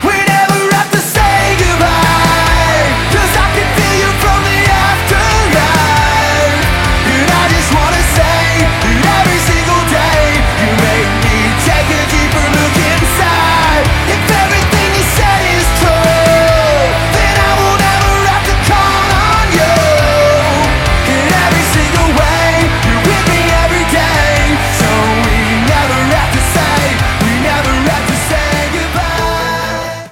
• Качество: 192, Stereo
Романтичная песня